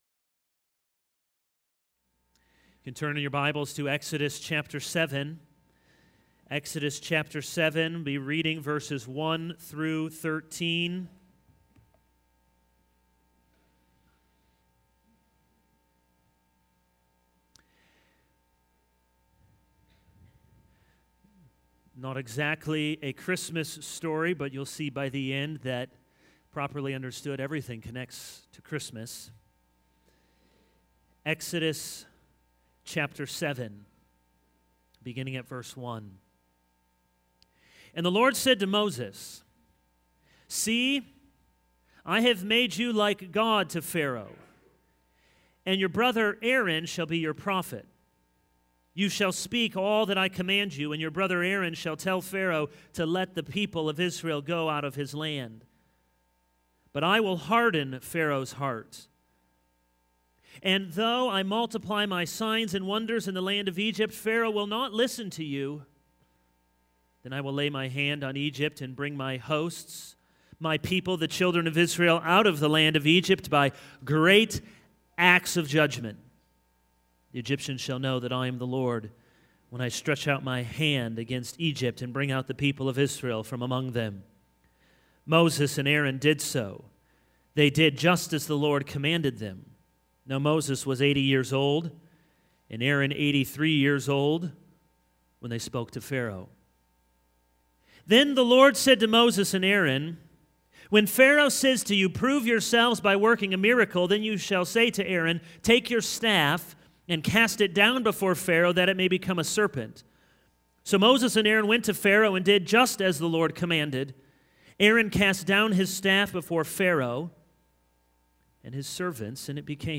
This is a sermon on Exodus 7:1-13.